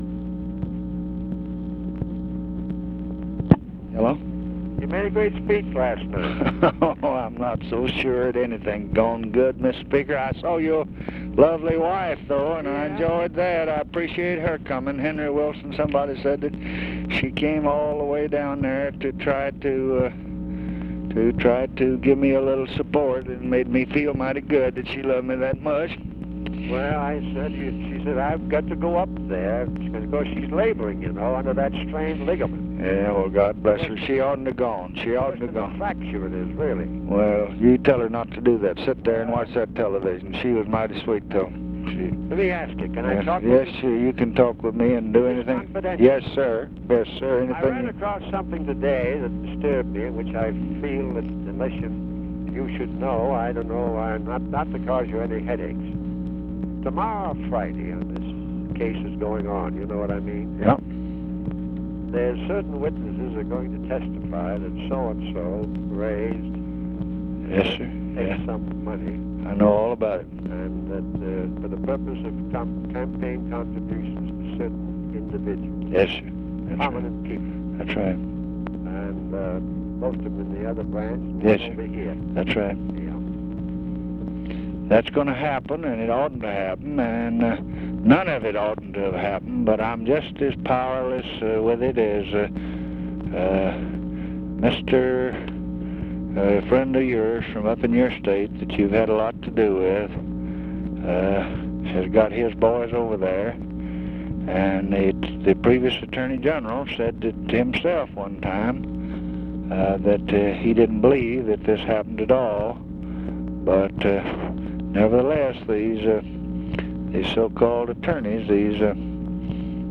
Conversation with JOHN MCCORMACK, January 12, 1967
Secret White House Tapes